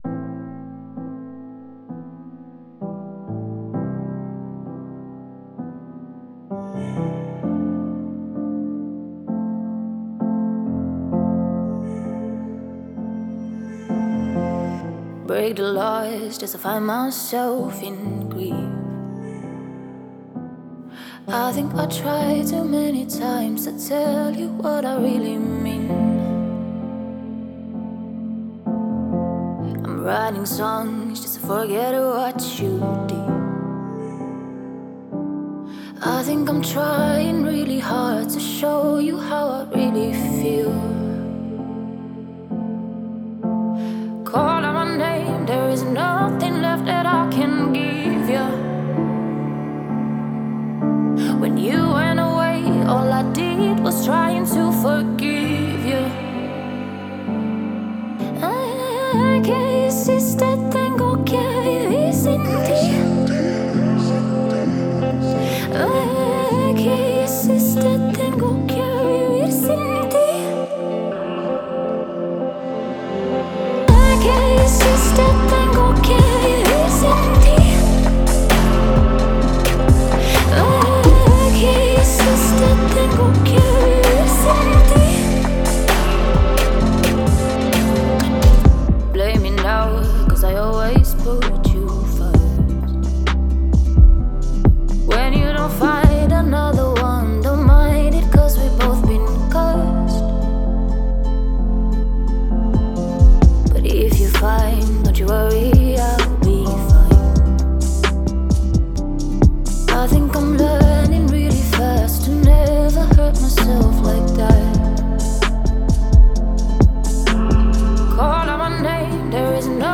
эмоциональная поп-песня